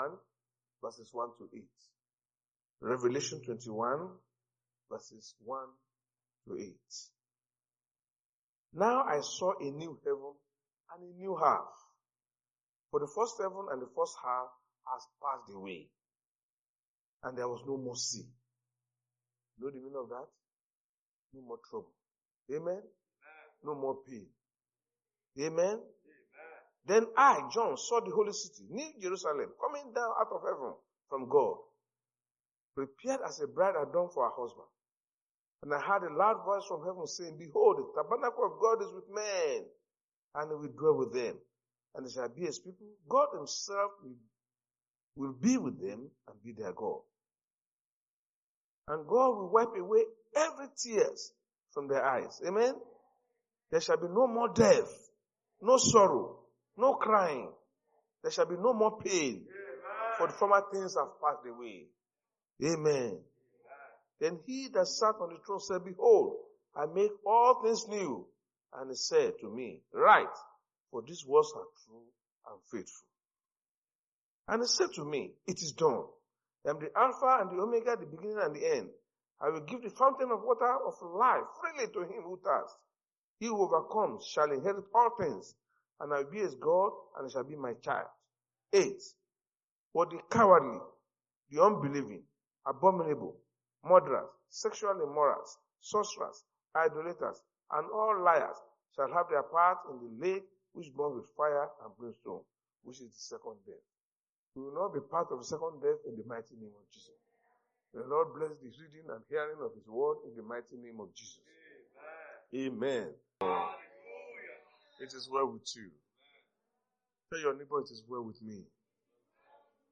Service Type: Sunday Church Service